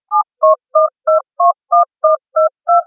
DTMF